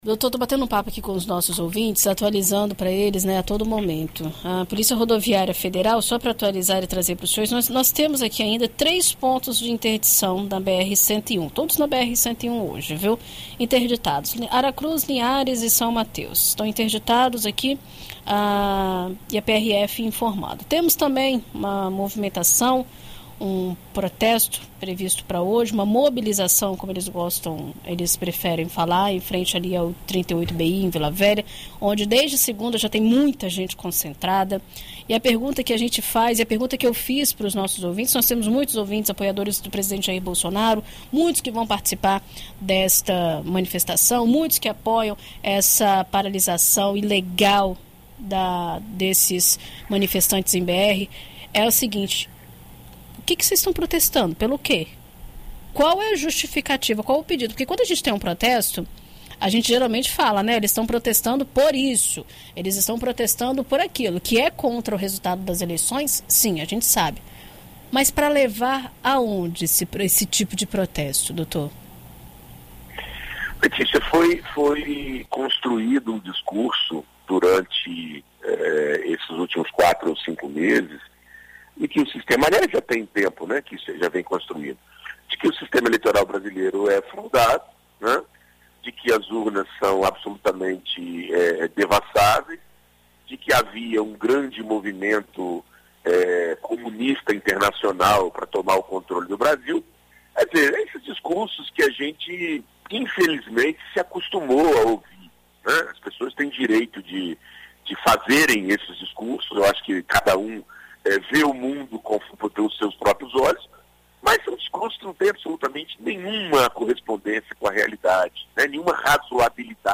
Direito para Todos: advogado analisa movimento a favor de golpe nas estradas federais